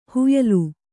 ♪ huyalu